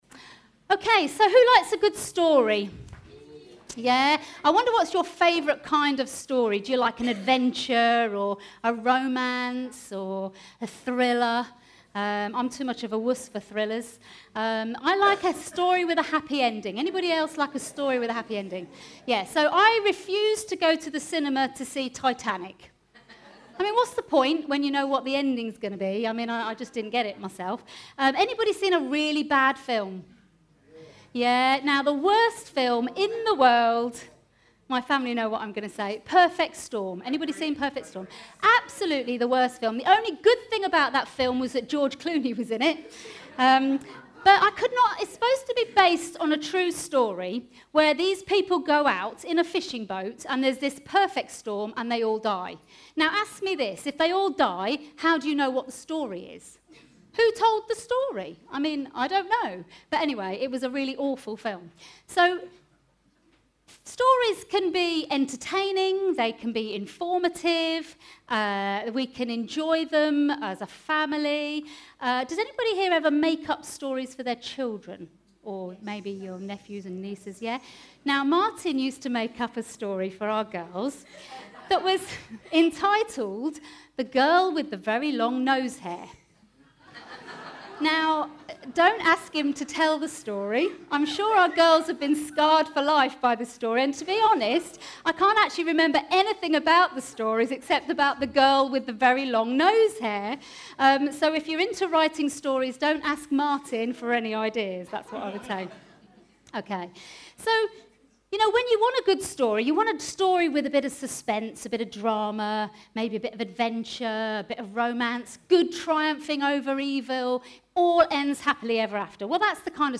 Baptismal Service - King's Centre